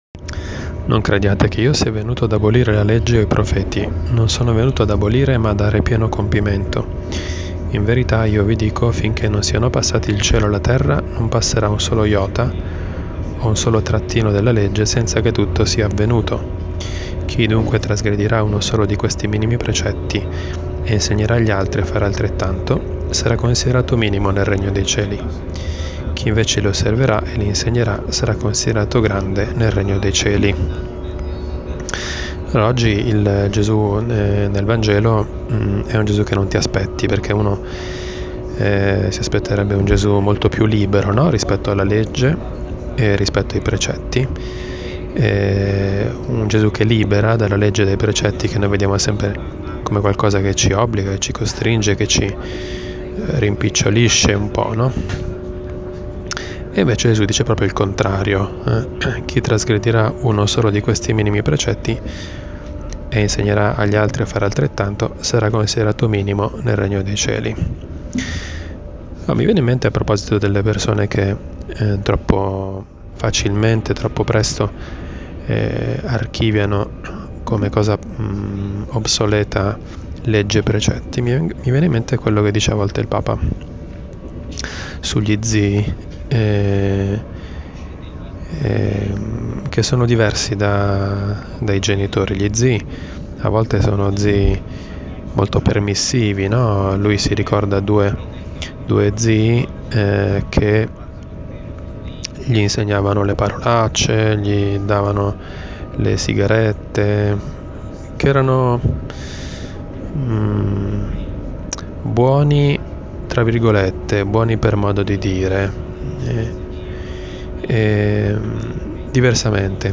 Commento al vangelo (Mt 5,17-19) del 7 marzo 2018, mercoledì della III settimana di Quaresima.